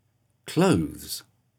The /ð/ sound: How to produce it .